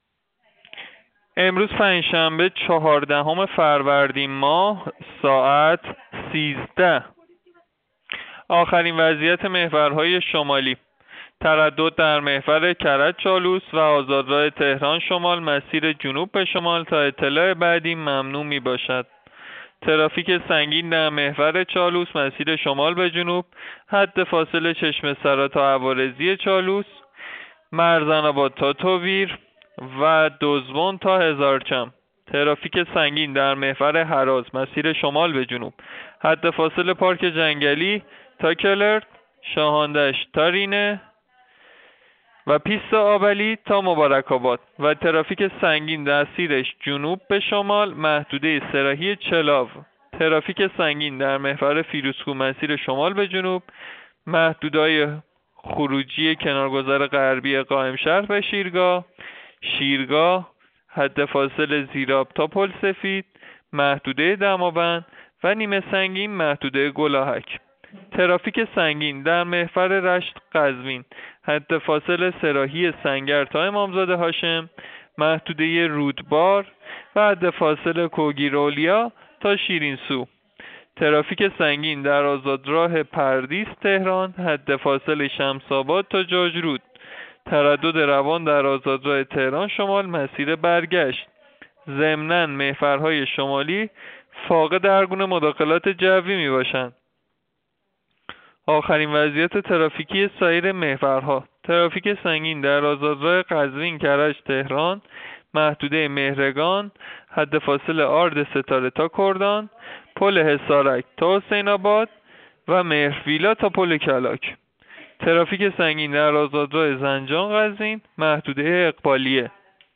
گزارش رادیو اینترنتی از آخرین وضعیت ترافیکی جاده‌ها ساعت ۱۳ چهاردهم فروردین؛